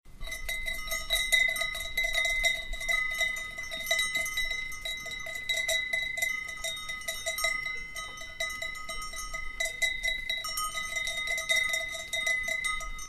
Dans cette page nous offrons des sonneries issues d’enregistrements de troupeaux.
Crète : 2 chèvres, cloche à 2 battants et kypri
crete_chevres-9a0.ogg